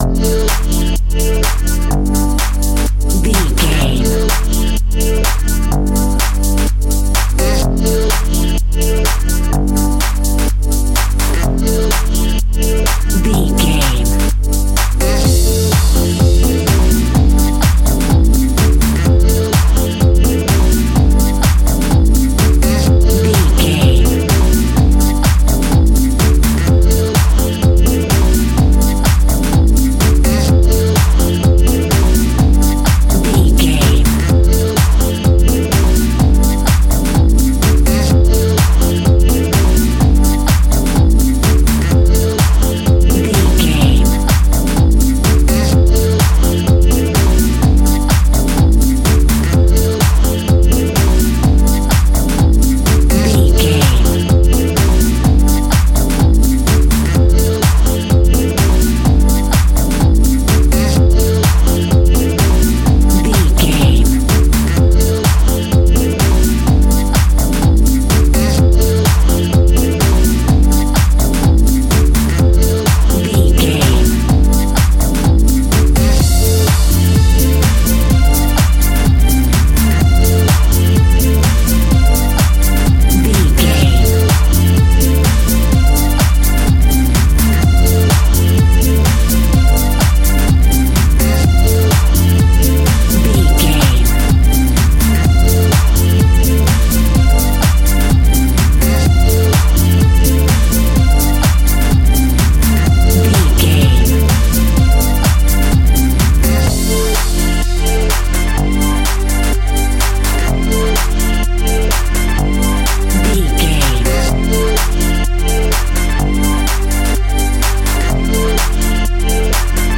Aeolian/Minor
D
groovy
hypnotic
uplifting
electric piano
synthesiser
bass guitar
horns
drum machine
disco house
electronic funk
energetic
upbeat
clavinet